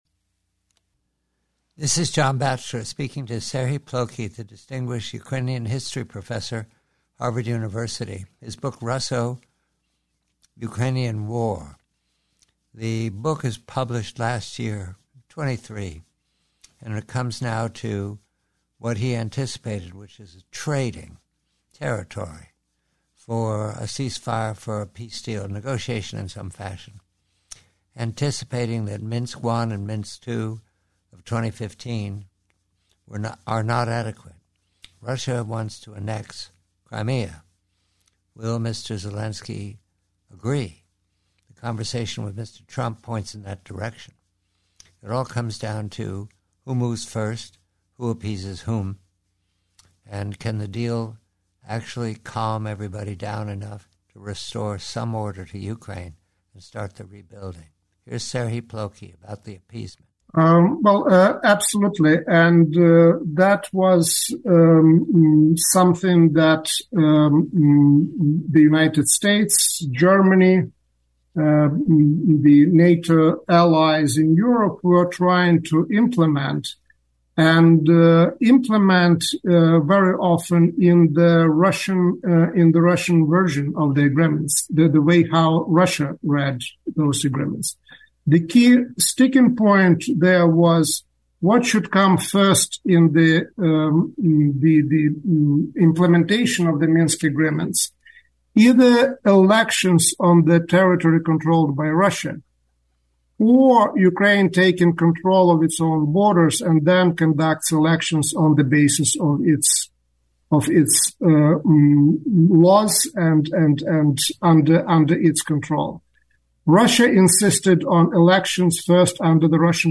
Preview: Conversation with Author Serhii Plokhy, "The Russo-Ukrainian War," re Minsk 1 and Minsk 2 and What Appeasement of Russia May Work.